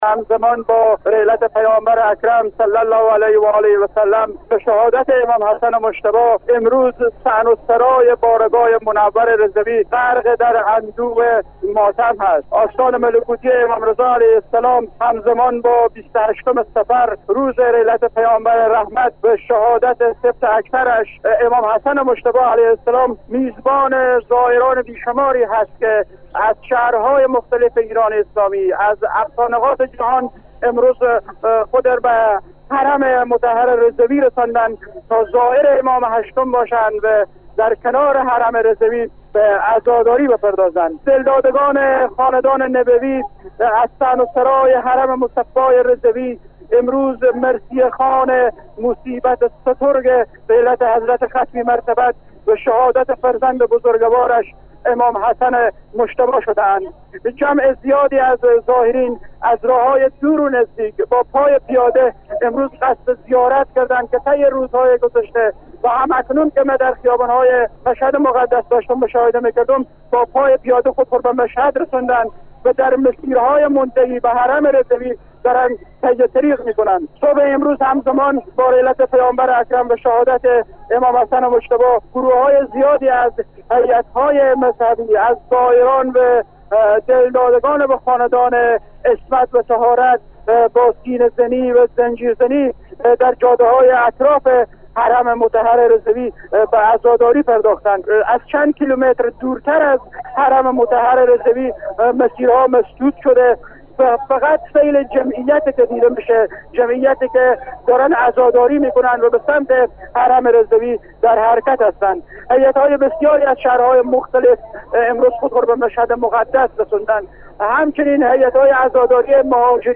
صبح امروز همزمان با رحلت پیامبر (ص) و امام حسن مجتبی (ع) گروههای زیادی از هیات های مذهبی، زائران و دلداگان خاندان عصمت و طهارت با سینه زنی و زنجیر زنی در مسیرهای اطراف حرم مطهر رضوی به عزاداری پرداختند.
گزارش